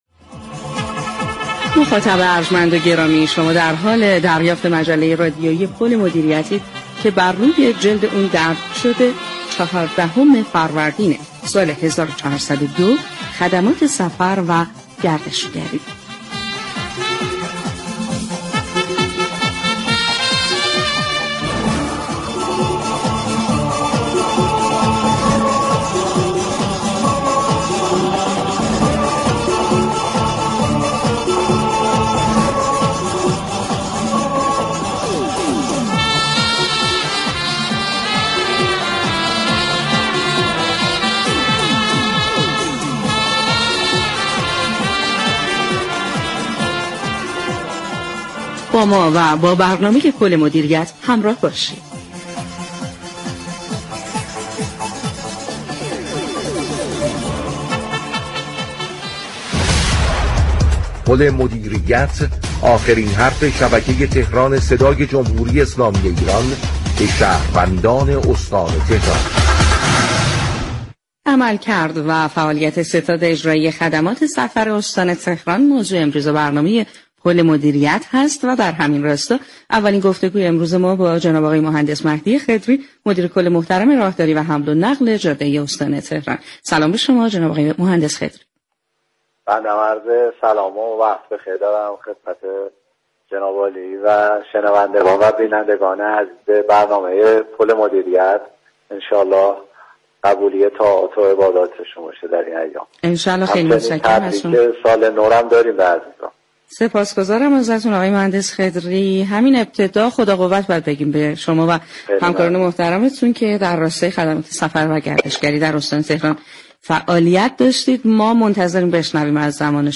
به گزارش پایگاه اطلاع رسانی رادیو تهران، برنامه پل مدیریت رادیو تهران در روز 14 فروردین با موضوع عملكرد و فعالیت ستاد اجرایی سفر استان تهران بر روی آنتن رادیو تهران رفت. در همین راستا این برنامه با مهندس مهدی خضری مدیركل حمل و نقل جاده ای استان تهران گفت و گو كرد.